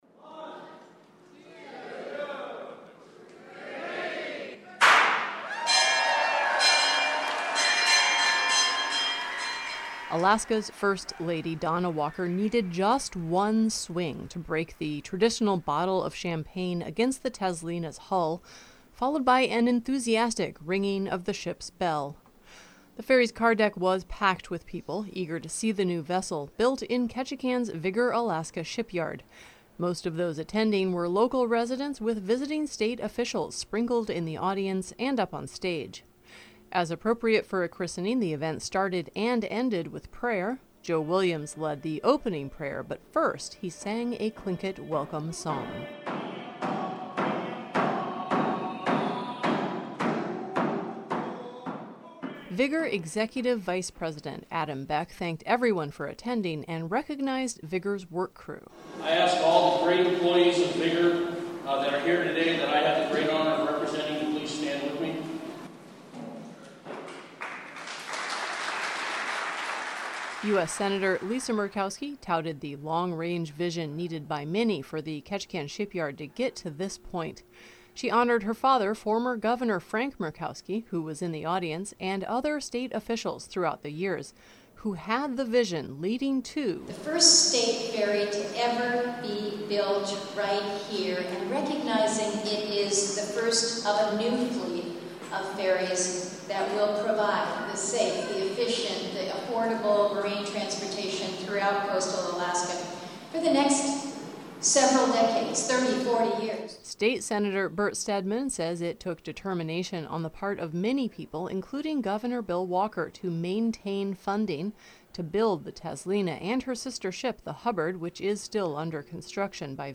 During a ceremony Saturday on board the vessel, Ketchikan residents and state officials gathered for the ferry’s christening ceremony.
The ferry’s car deck was packed with people eager to see the new vessel, built in Ketchikan’s Vigor Alaska shipyard.
As appropriate for a christening, the event started and ended with prayer.
U.S. Sen. Lisa Murkowski touted the long-range vision needed by many for the Ketchikan shipyard to get to this point.